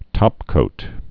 (tŏpkōt)